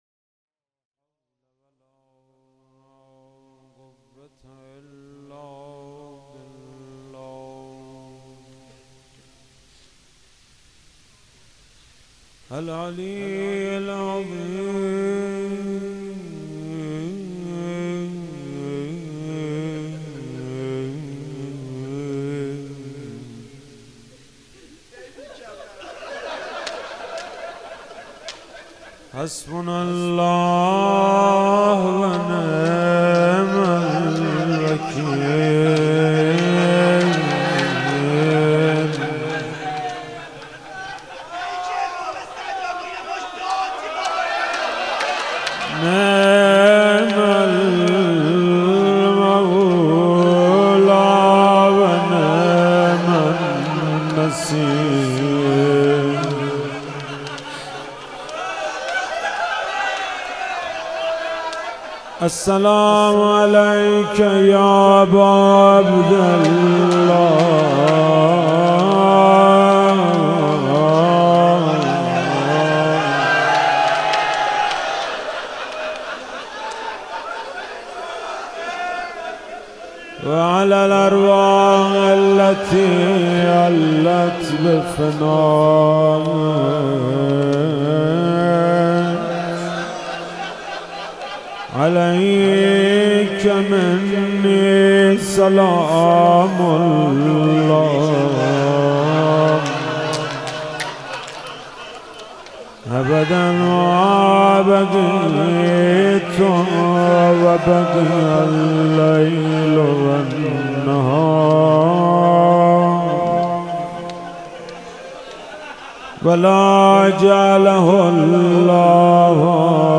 شهادت امام کاظم(ع)98 - روضه